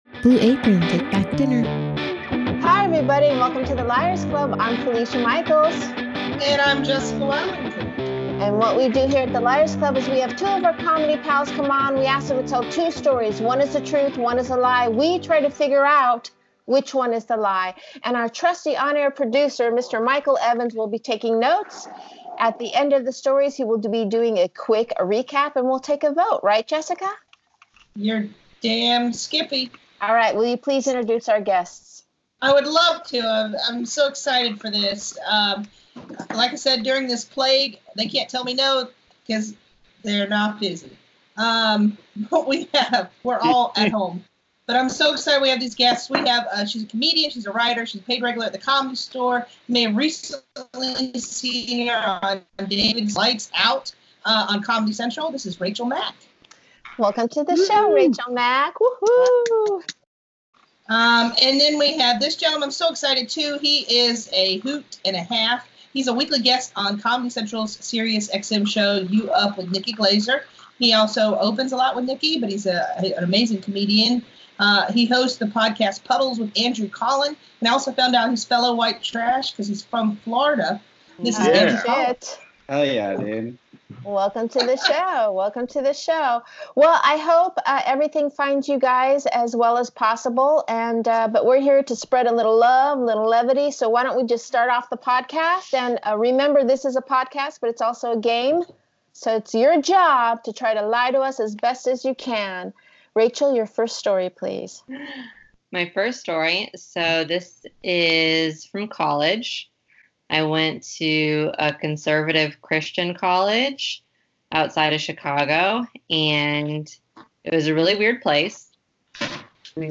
We will be releasing our Skype and Zoom Sessions through-out the quarantine. Please bear with us as well continually improve the quality of the recordings.